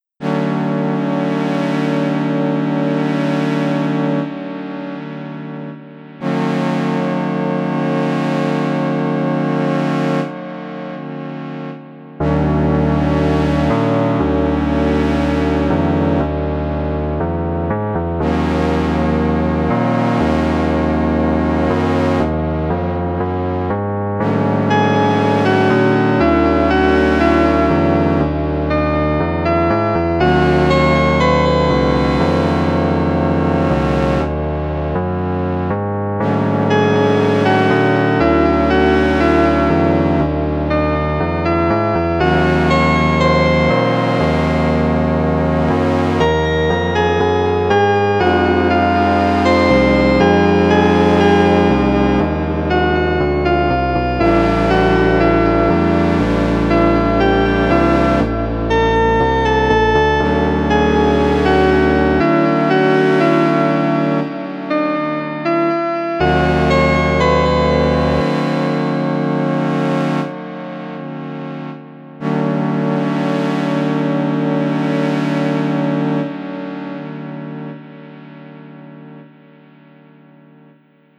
One-hour challenge composition
City_prompt_19edo.mp3